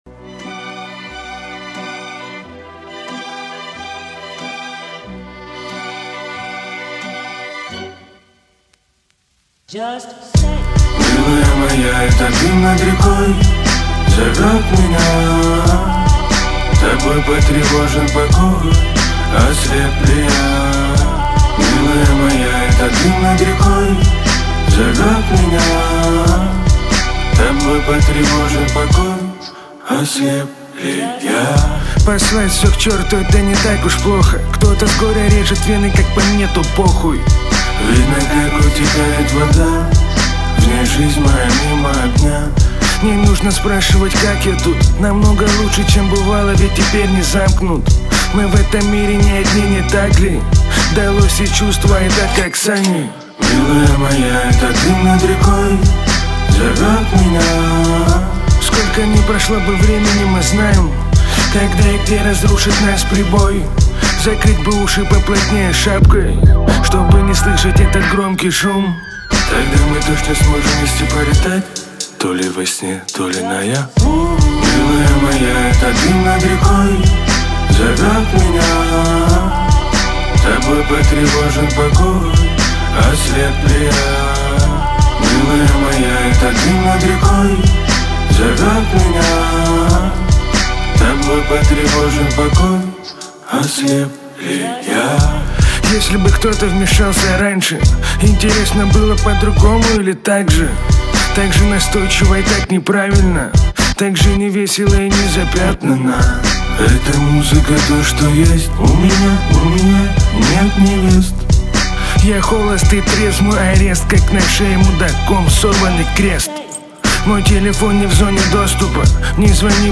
погружает слушателя в атмосферу ностальгии и романтики